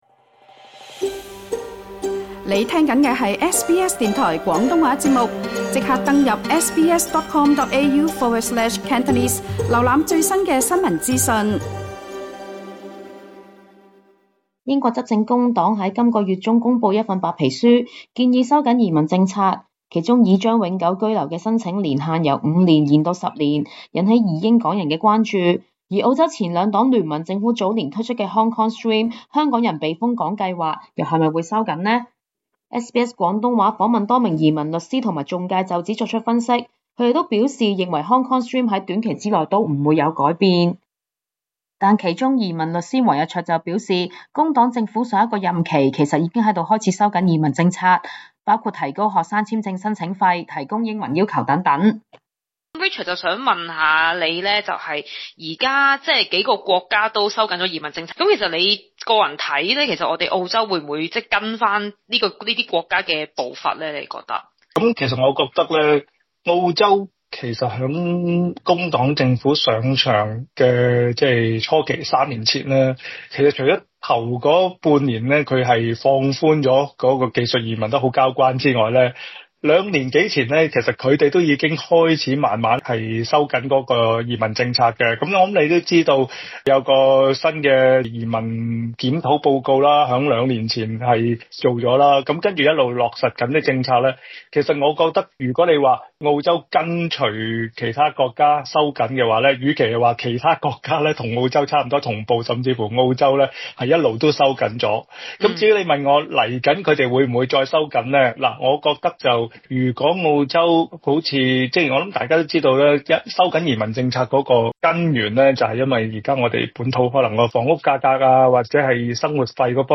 多名移民律師及仲介對於政府未來的移民政策方向、港人簽證的審批情況都有分析，詳情請聽錄音訪問內容。